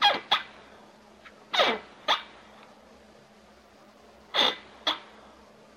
椅子的挤压和呻吟" smallsqueeks
描述：一把挤压的旧办公椅，在旧货店买的。舒适，但声音很大。用索尼IC录音机录制，并对嘶嘶声进行过滤。一系列短而安静的挤压声或尖叫声。
Tag: 效果 呻吟 呻吟 LOFI squeek squeeky squeel